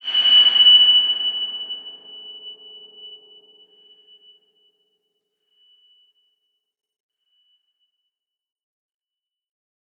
X_BasicBells-F#5-mf.wav